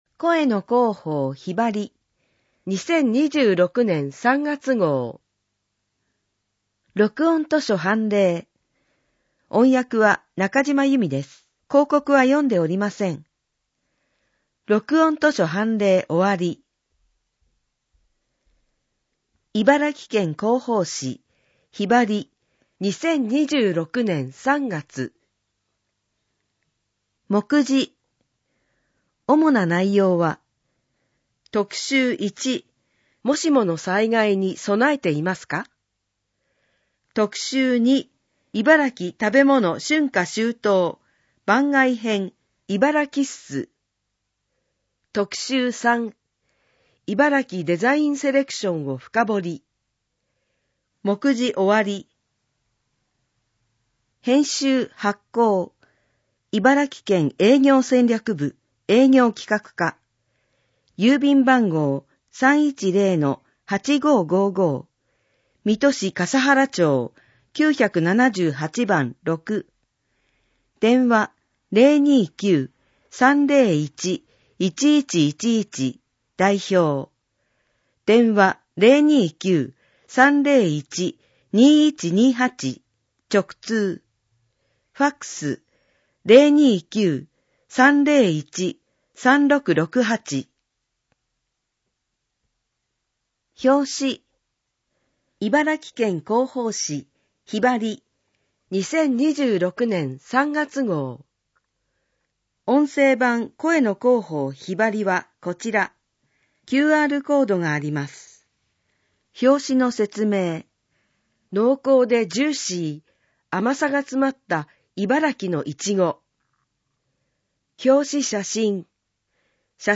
【お知らせひろば】 県からのお知らせ 【催し物ガイド】 県内の美術館・博物館などの企画展をご紹介 【その他トピックス】知事コラム、誇れるいばらき、クロスワードパズルなど 音声版・点字版・電子版県広報紙「ひばり」 音声版 視覚障害の方を対象に音声版も発行しています。 声の広報「ひばり」 音声を再生するためには、 「QuicktimePlayer」（外部サイトへリンク） 、 「WindowsMediaPlayer」（外部サイトへリンク） 、 「RealPlayer」（外部サイトへリンク） （いずれも無料）などが必要です。